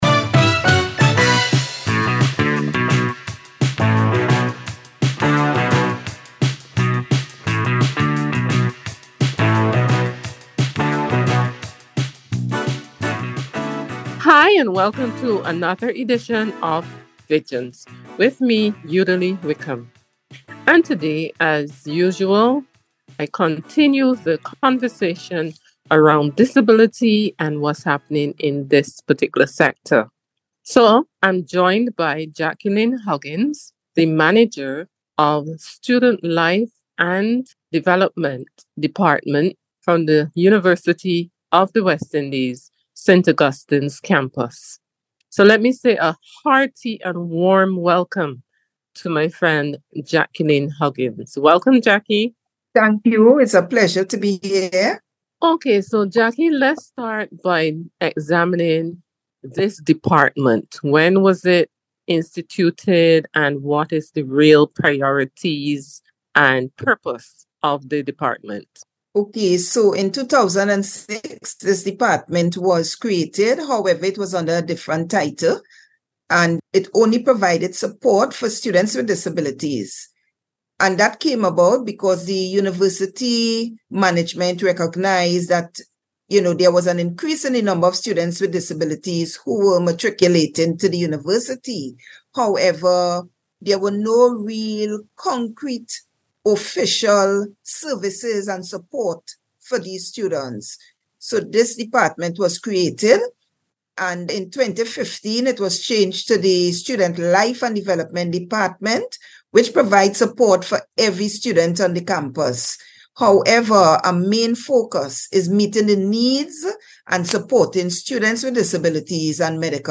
Visions Aug 20th 2023 - Interview